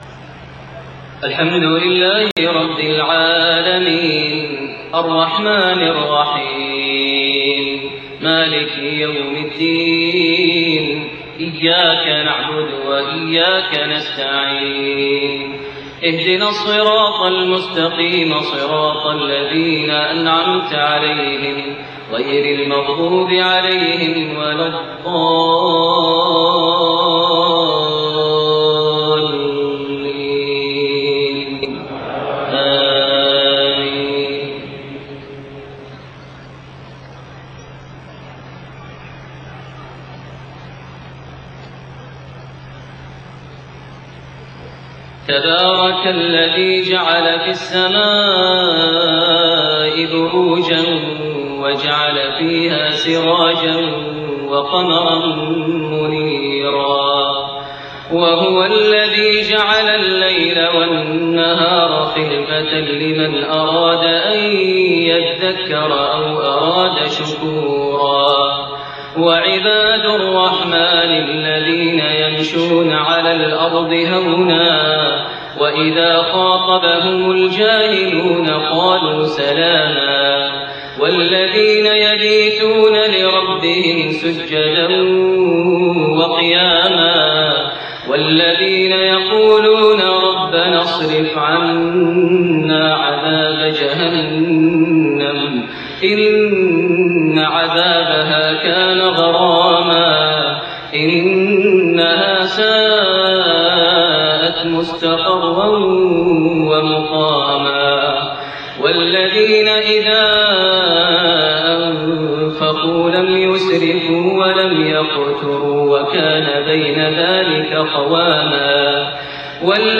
Isha prayer from Surat Al-Furqaan > 1429 H > Prayers - Maher Almuaiqly Recitations